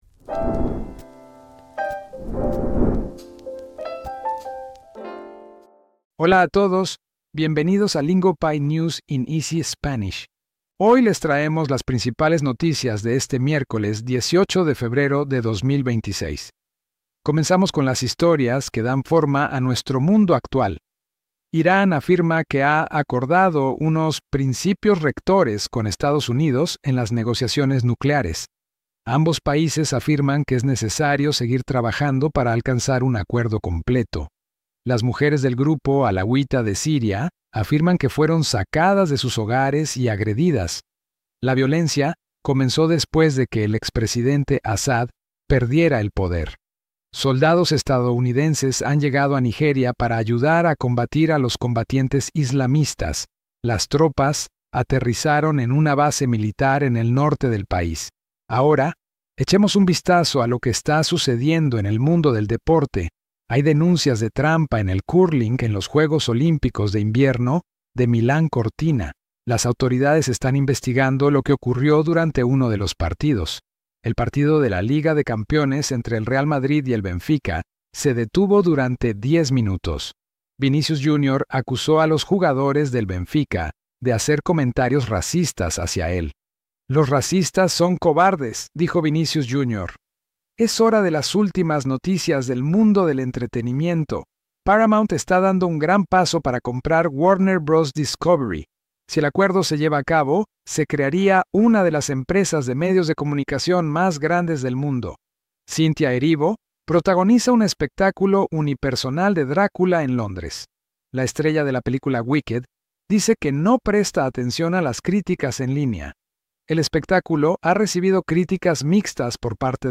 Lingopie’s News in Easy Spanish is your go-to slow Spanish news podcast for staying informed while actually improving your Spanish listening. Today’s biggest global headlines, delivered in clear and beginner-friendly Spanish.